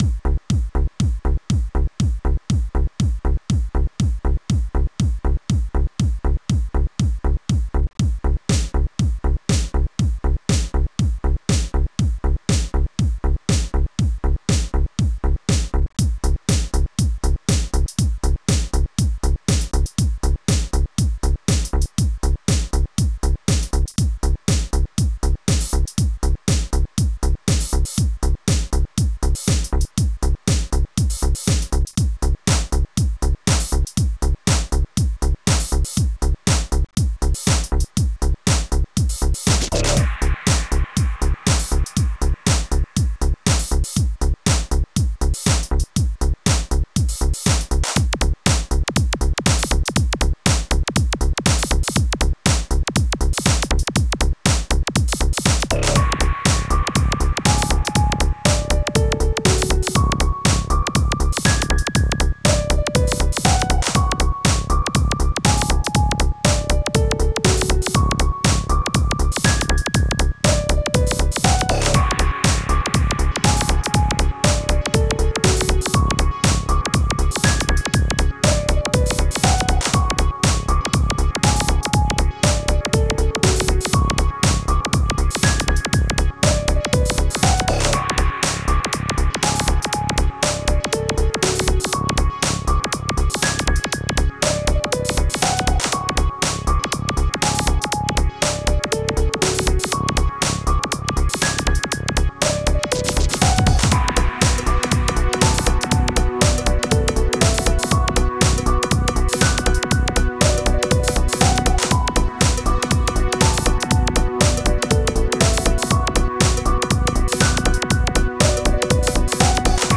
4:14 Style: Electronic Released
A weird, experimental track.